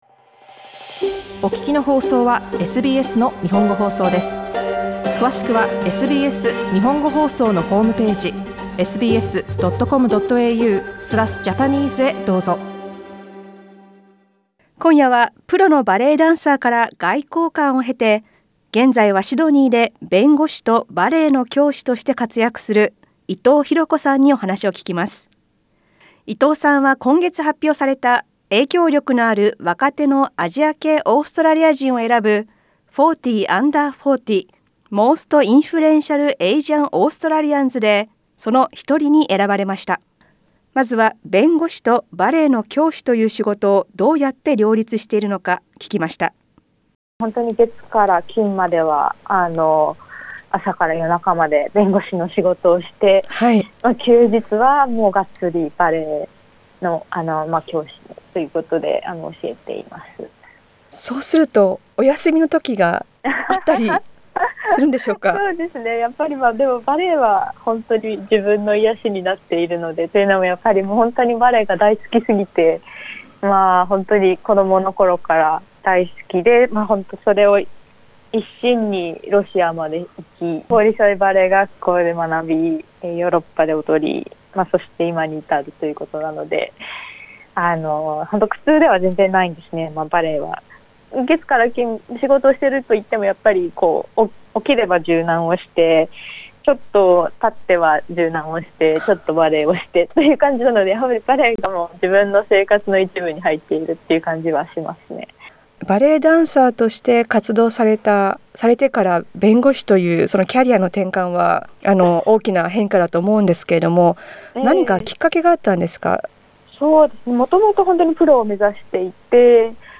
インタビューでは、弁護士になった理由や、チャリティーレッスンなどについて聞きました。